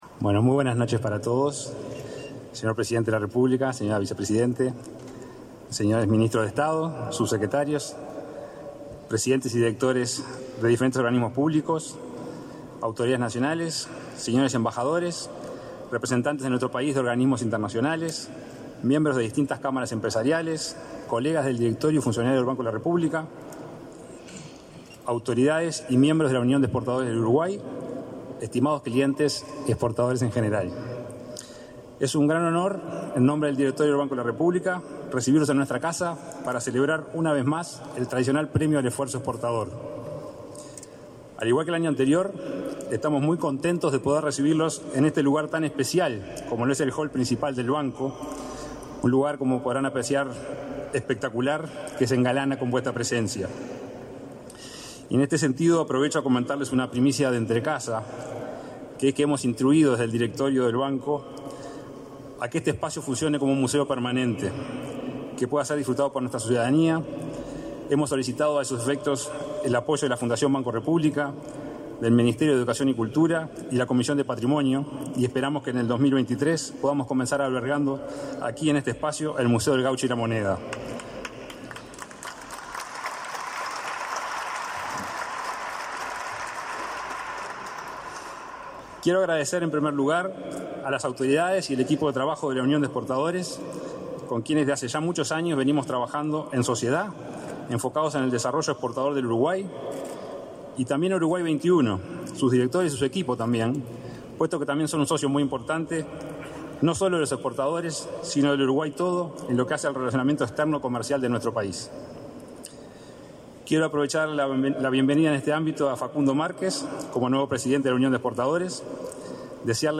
Ceremonia de entrega de Premios al Esfuerzo Exportador